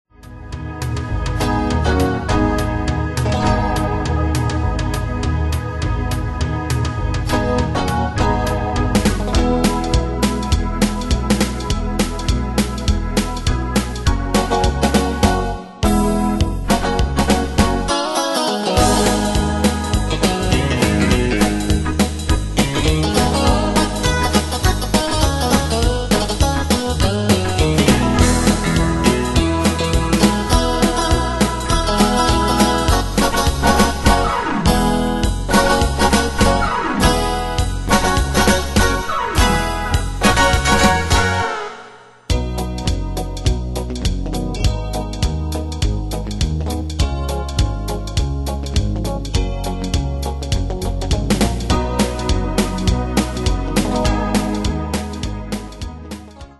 Style: Country Année/Year: 2000 Tempo: 102 Durée/Time: 3.18
Pro Backing Tracks